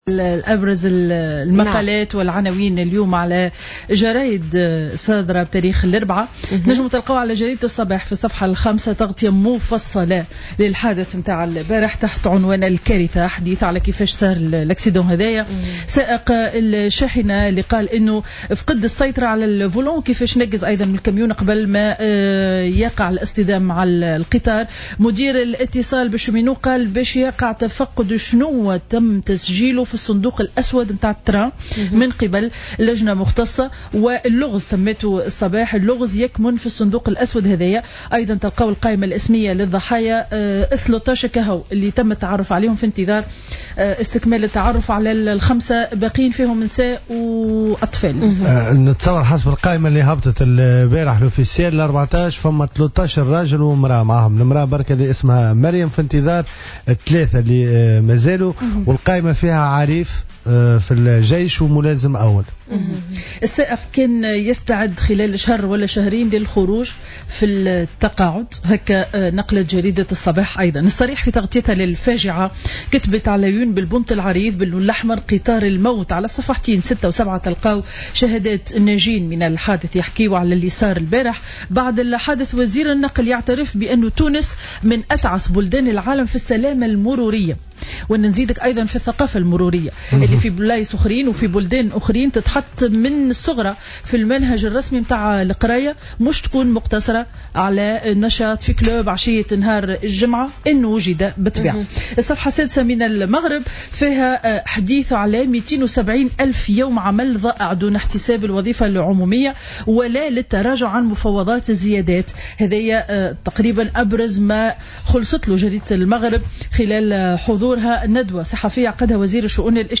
Revue de presse du 17 Juin 2015